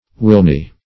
Search Result for " wilne" : The Collaborative International Dictionary of English v.0.48: Wilne \Wil"ne\, v. t. [AS. wilnian.]